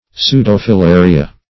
Search Result for " pseudofilaria" : The Collaborative International Dictionary of English v.0.48: Pseudofilaria \Pseu`do*fi*la"ri*a\, n.; pl.
pseudofilaria.mp3